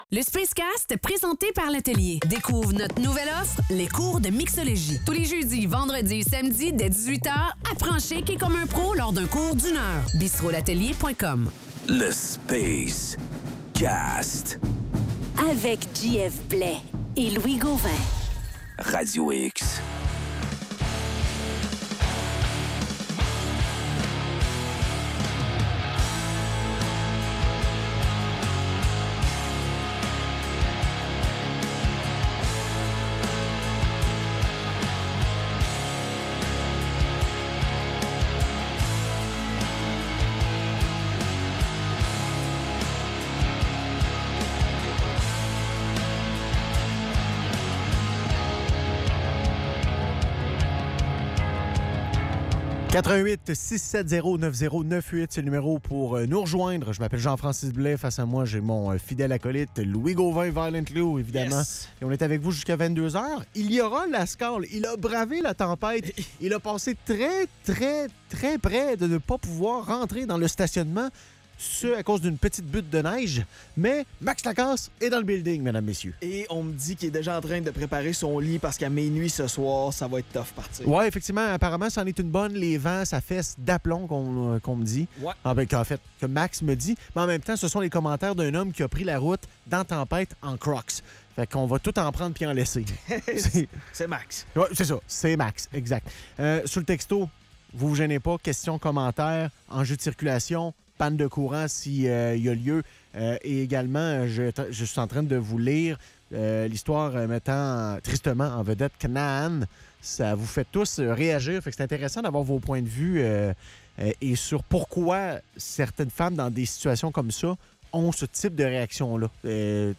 Entrevue avec Aiemann Zahabi, combattant de la UFC.